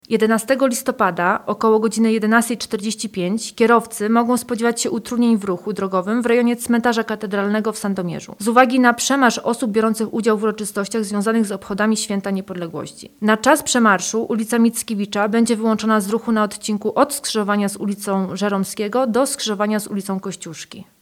Mówi sierżant sztabowy